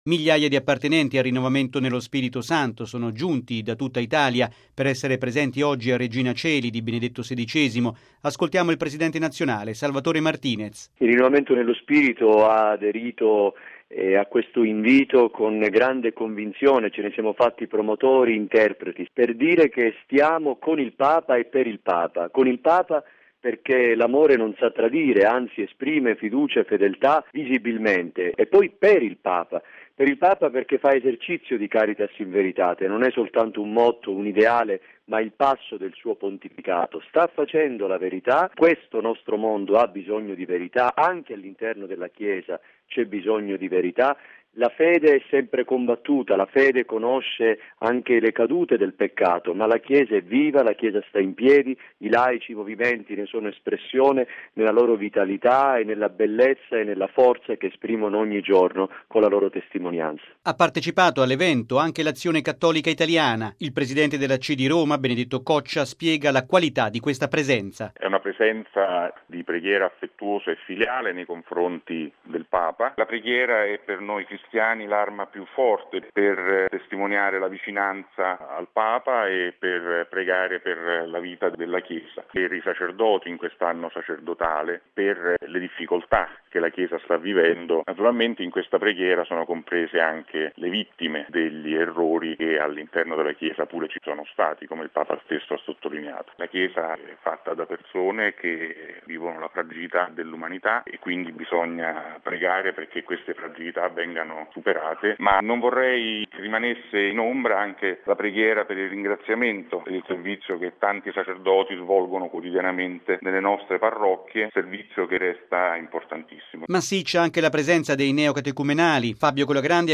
In Piazza San Pietro dunque tanti movimenti, associazioni, comunità ecclesiali e parrocchiali provenienti da ogni parte d’Italia.